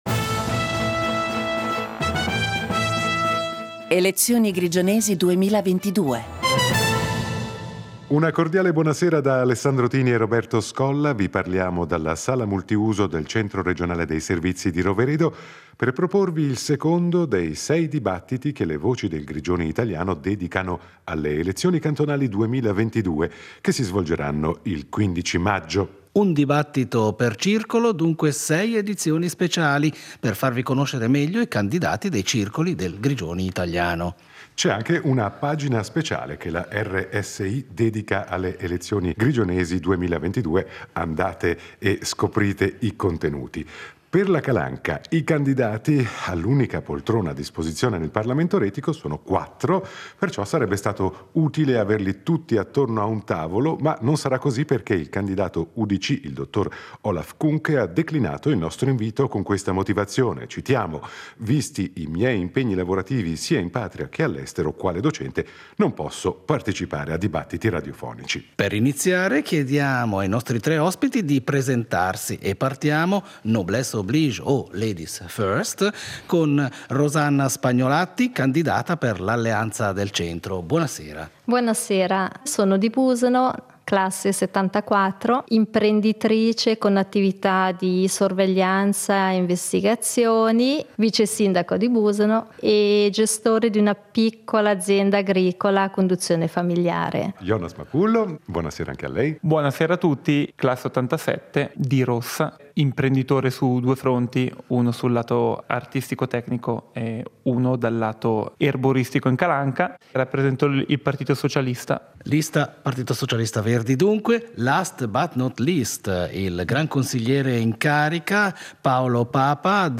Dibattito in vista delle elezioni retiche del 15 maggio 2022